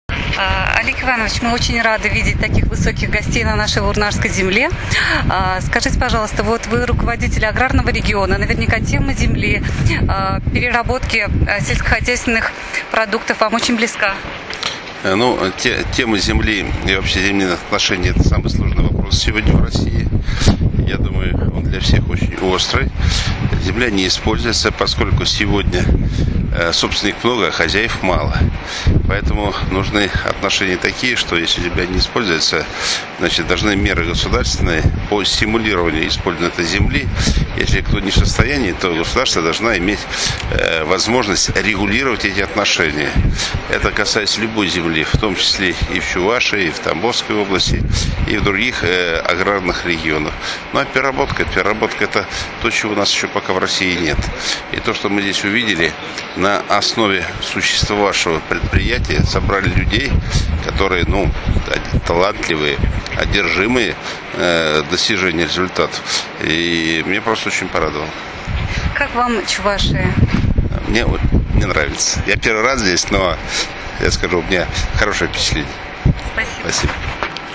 На предприятии состоялось интервью,  губернатор Тамбовской области Олег Бетин ответил на вопросы (
аудиозапись интервью):